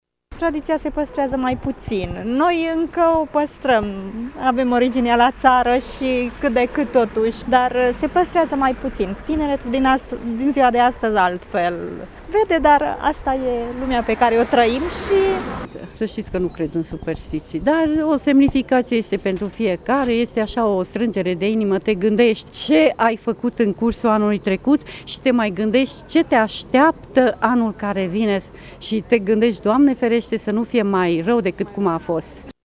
Mureşenii spun că mai păstrează unele tradiţii, dar şi că nu sunt foarte superstiţioşi: